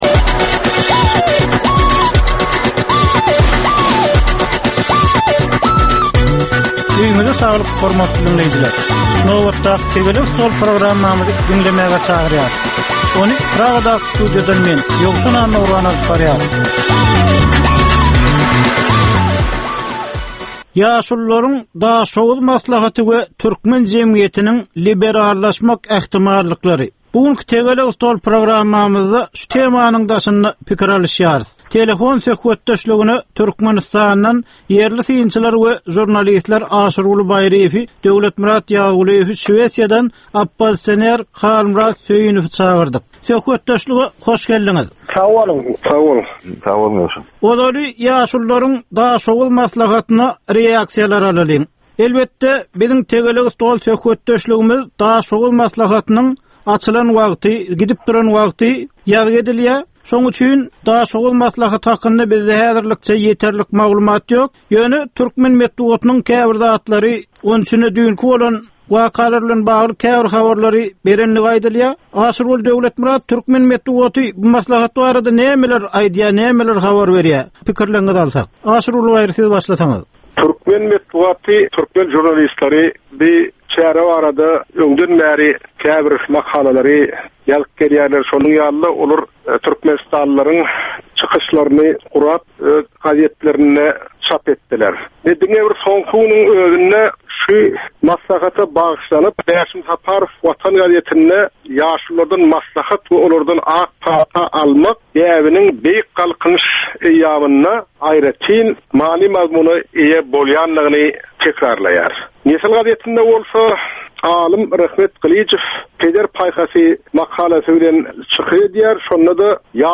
Jemgyýetçilik durmuşynda bolan ýa-da bolup duran soňky möhum wakalara ýa-da problemalara bagyşlanylyp taýýarlanylýan ýörite diskussiýa. 30 minutlyk bu gepleşikde syýasatçylar, analitikler we synçylar anyk meseleler boýunça öz garaýyşlaryny we tekliplerini orta atýarlar.